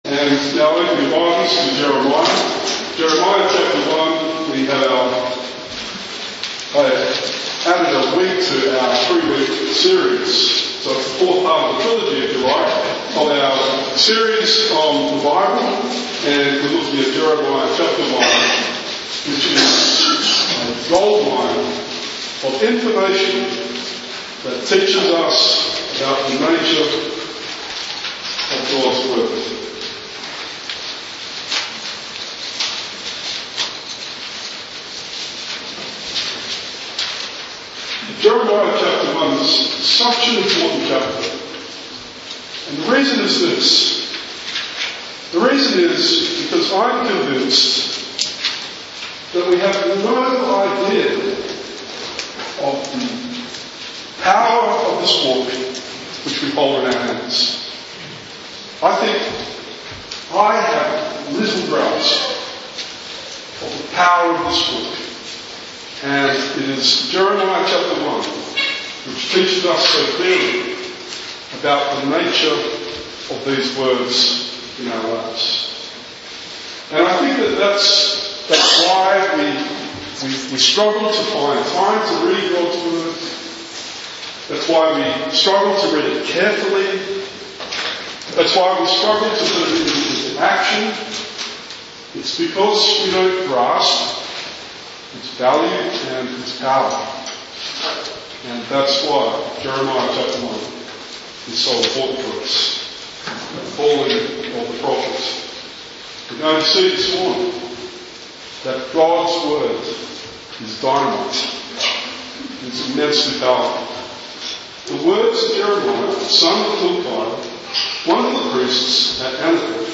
Jeremiah 1:1-19 Sermon